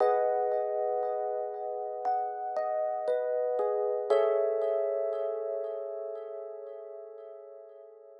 04 ElPiano PT3.wav